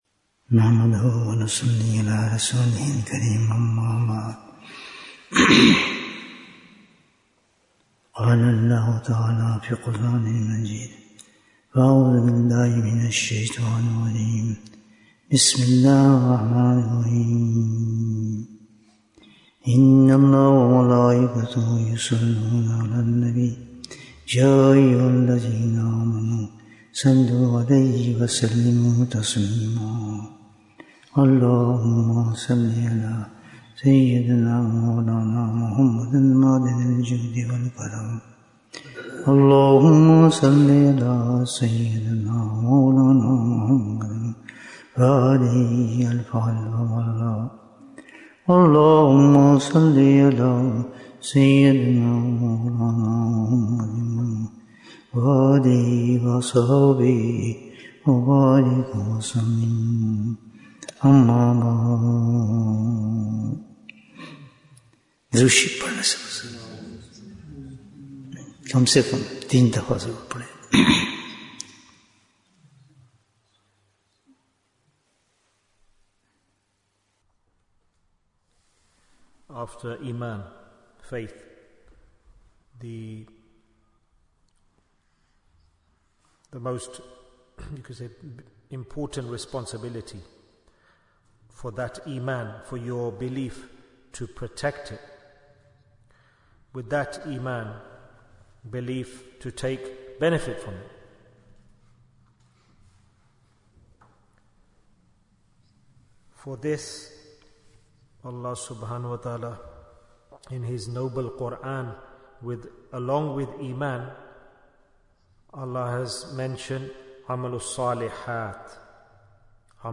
Jewels of Ramadhan 2025 - Episode 15 - Fruits of Intentions Bayan, 62 minutes12th March, 2025